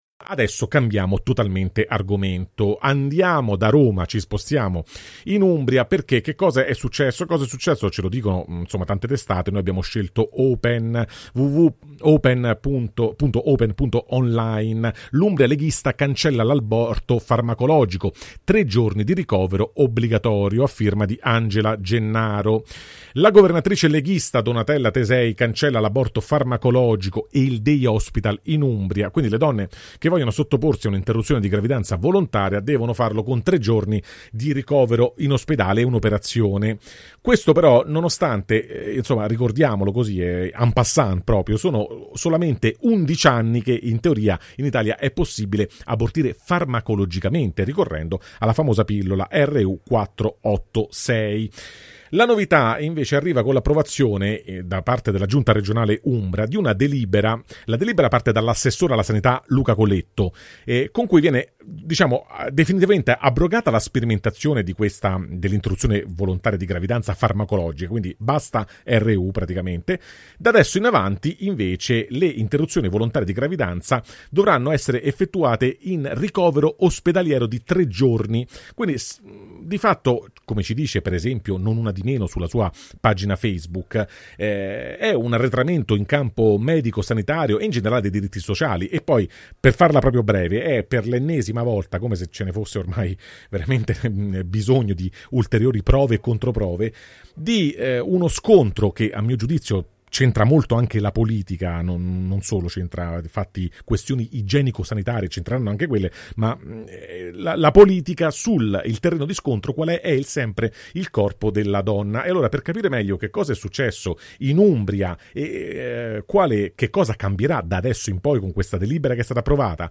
11 anni e non sentirli. La pillola abortiva e il caso Umbria [Intervista